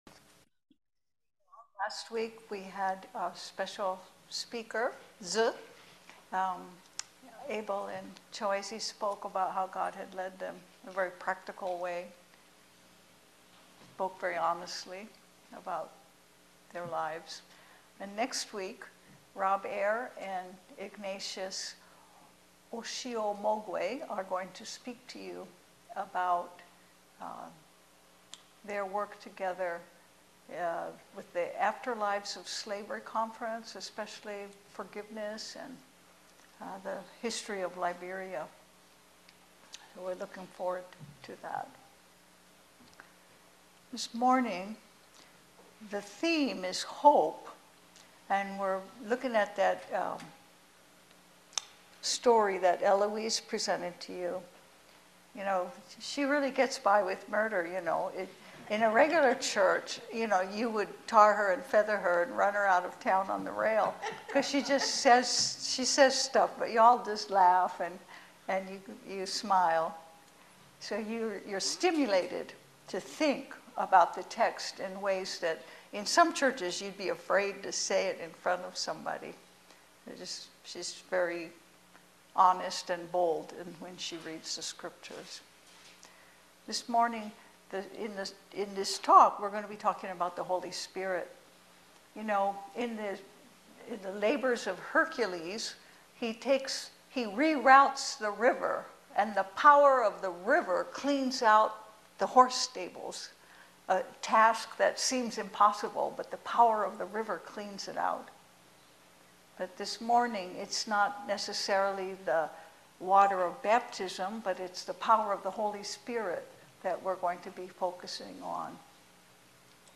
1-8 Service Type: Sunday Service The process of God’s Word takes time.